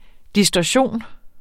Udtale [ disdɒˈɕoˀn ]